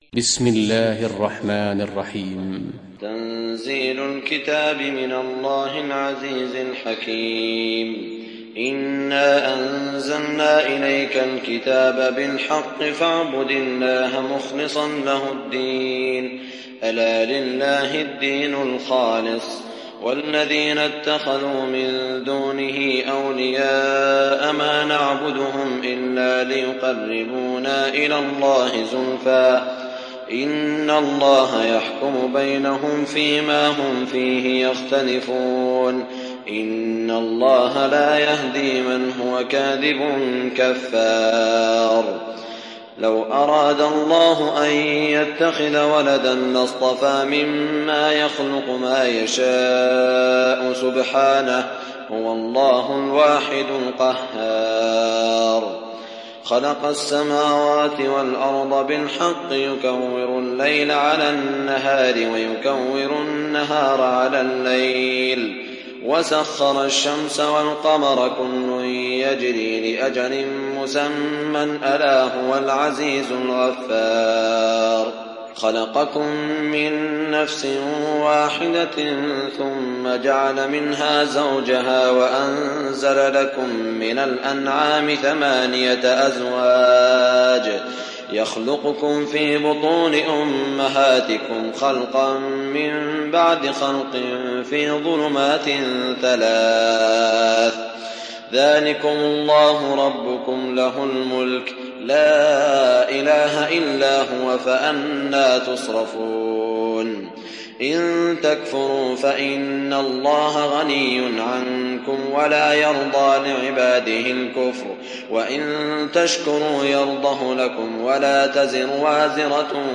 تحميل سورة الزمر mp3 بصوت سعود الشريم برواية حفص عن عاصم, تحميل استماع القرآن الكريم على الجوال mp3 كاملا بروابط مباشرة وسريعة